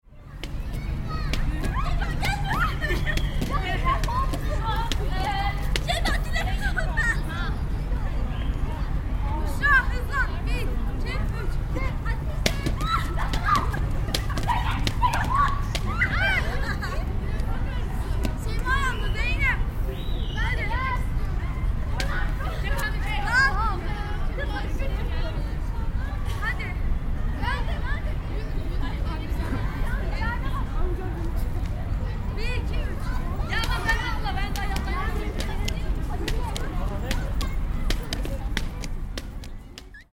Tulip festival, Emirgan park,
Among the gloriuos and lush tulips there were teenaged girls skipping rope.
The rope hitting the pavement is accompanied by giggles and laughter. On the background there are whistles of the park guards and families having a picnic, strolling around.